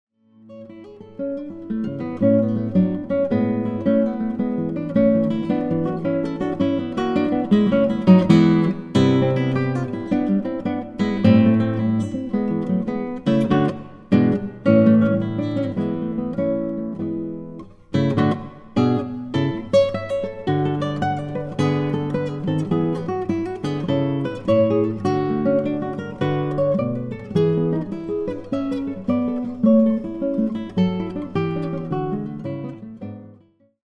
cedar/Brazilian guitar
deep, rich timbre